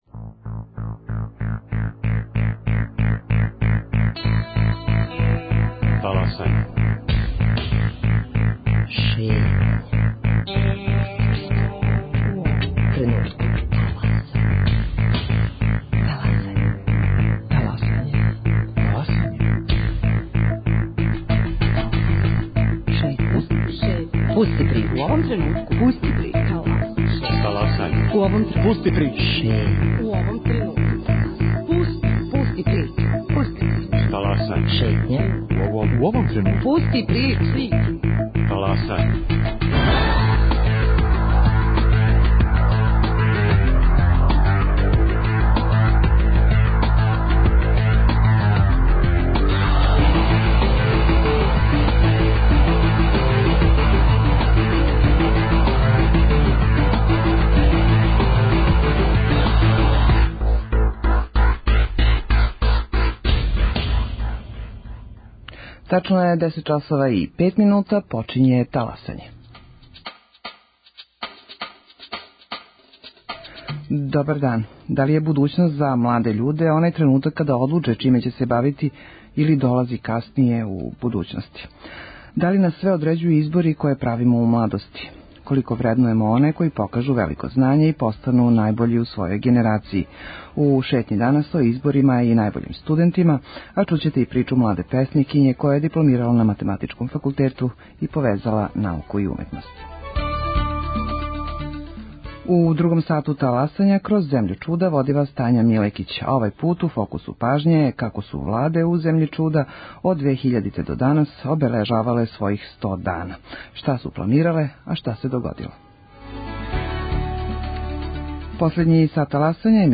гошћа у студију